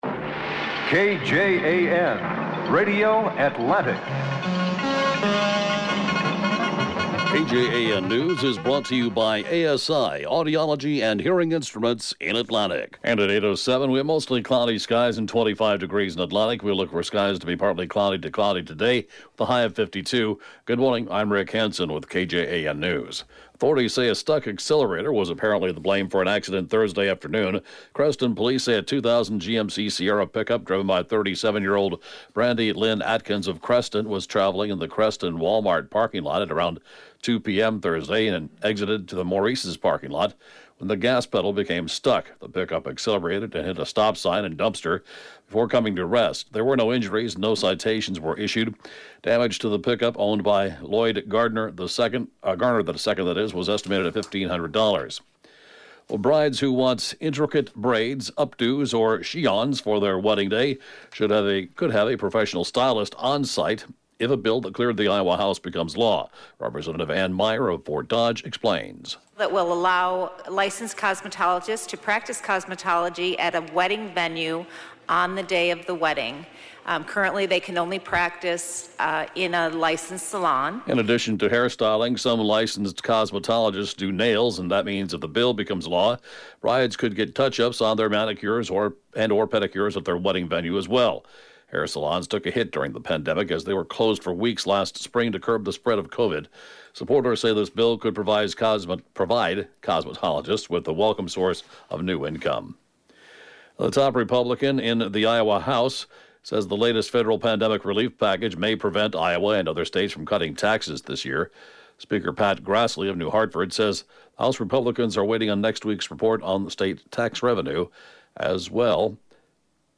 The 8:06-a.m. newscast